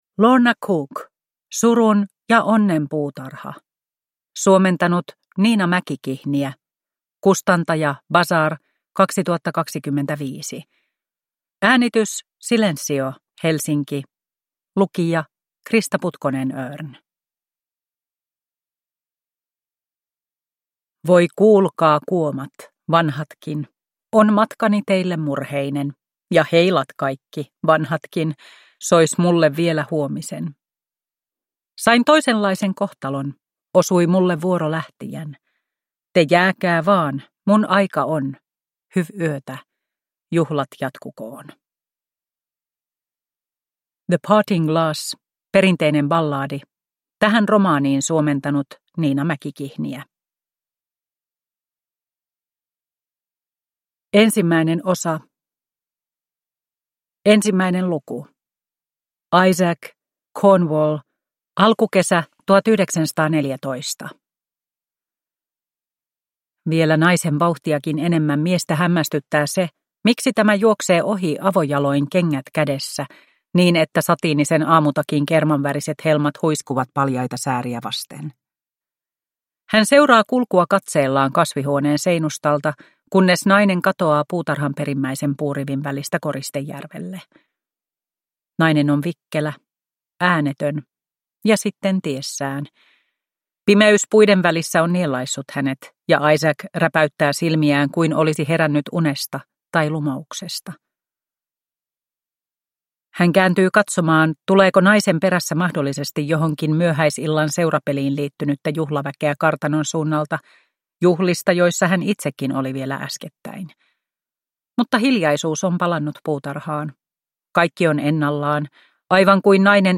Surun ja onnen puutarha (ljudbok) av Lorna Cook